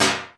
METAL.P #04.wav